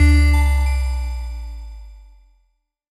Bass Power On Goodbye Bell.wav